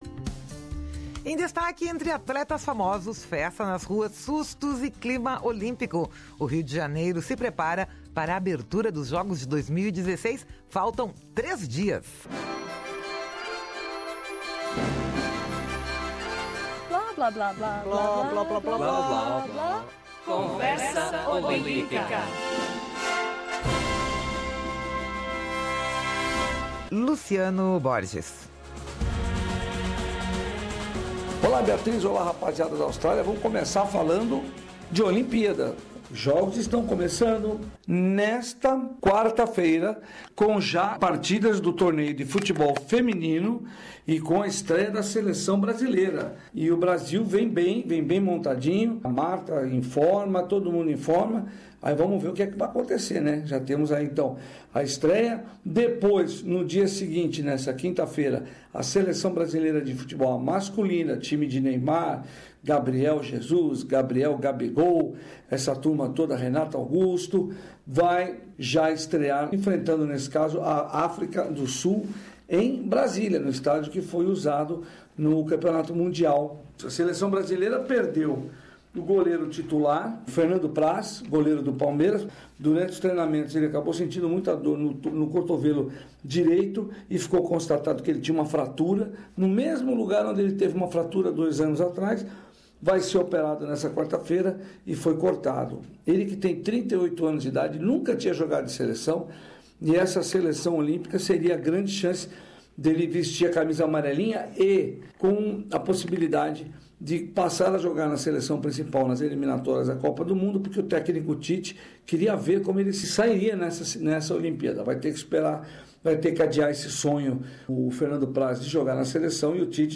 Conversa Olímpica: Entre atletas famosos, festa nas ruas, sustos e clima olímpico, o Rio de Janeiro se prepara para a abertura dos Jogos de 2016 neste sábado, 9 da manhã, no horário australiano